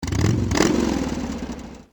Bike.ogg